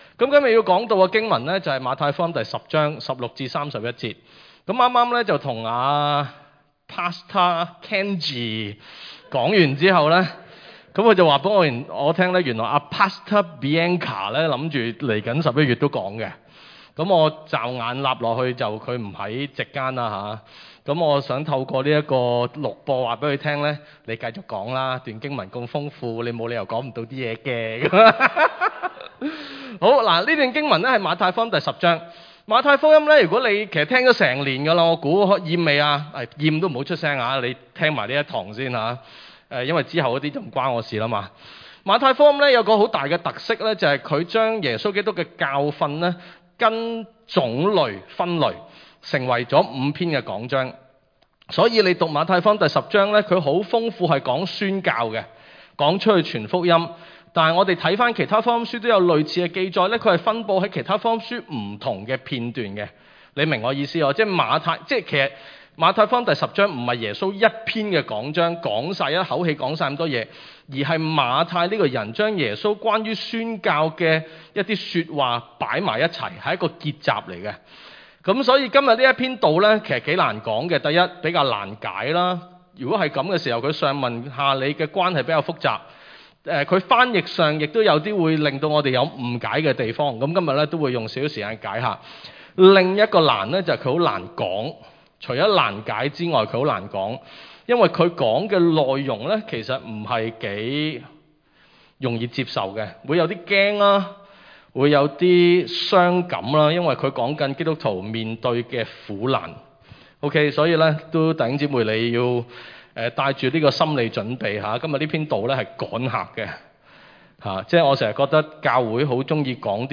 場所：週六崇拜